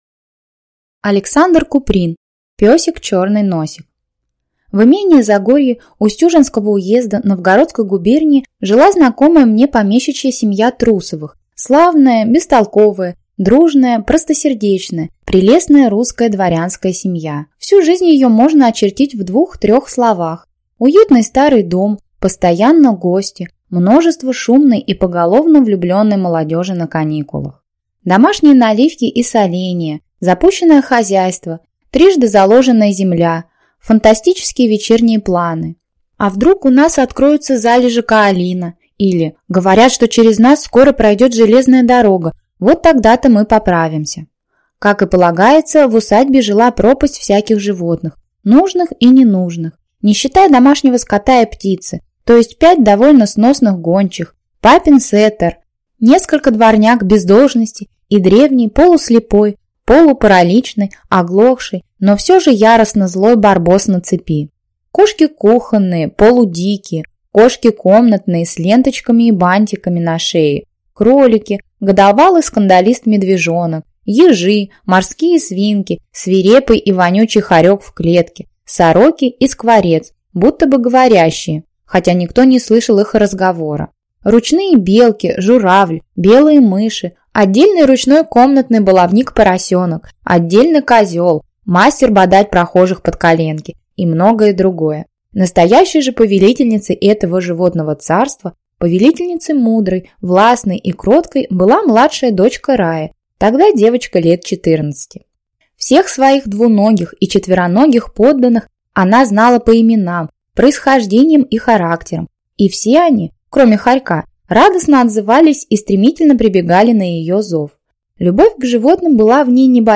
Аудиокнига Песик – Чёрный Носик | Библиотека аудиокниг